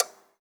clock_tock_04.wav